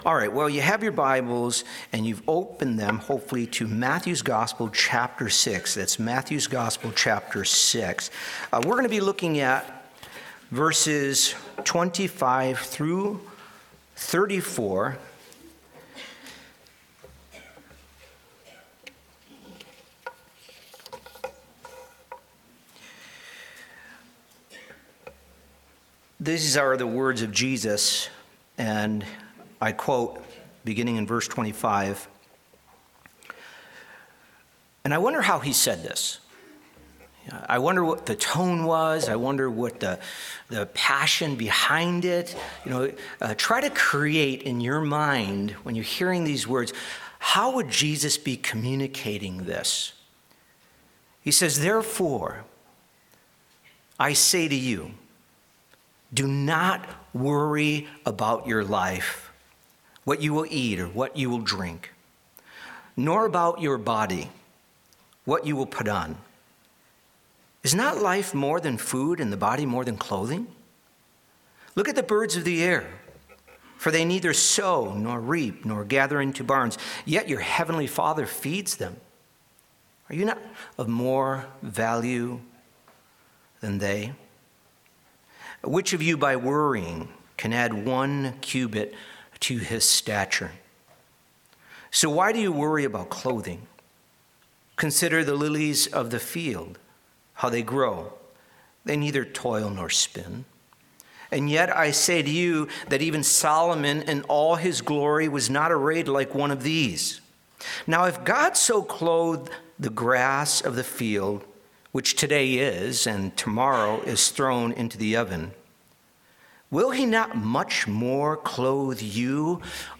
A message from the series "Topical Message."